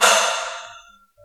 Tunk
hit hospital percussion sound effect free sound royalty free Memes